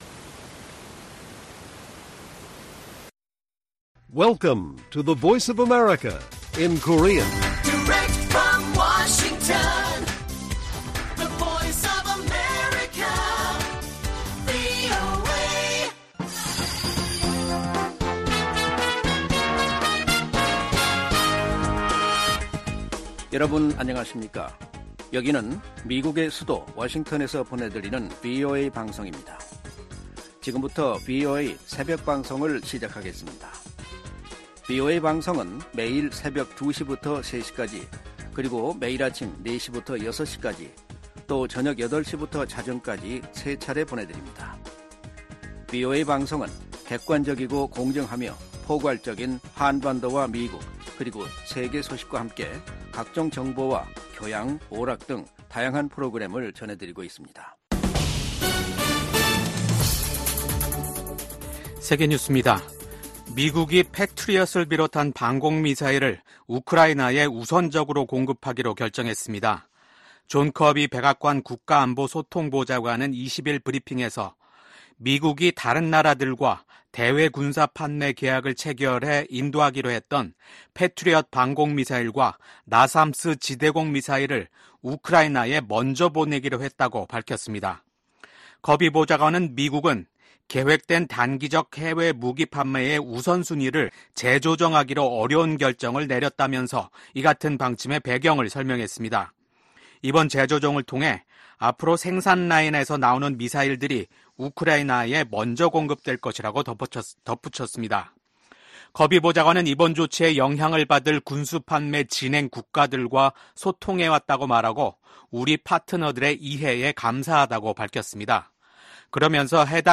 VOA 한국어 '출발 뉴스 쇼', 2024년 6월 22일 방송입니다. 북한과 러시아가 군사협력 조약을 체결한 데 대해 미국 백악관과 국무부, 국방부등이 우려를 나타냈습니다. 국무부는 우크라이나에 대한 한국의 무기 지원 여부는 한국이 결정할 사안이라고 말했습니다. 한국 정부는 북한과 동맹에 준하는 조약을 체결한 러시아를 규탄하고 우크라이나에 살상무기를 지원하지 않는다는 기존 방침을 재검토하기로 했습니다.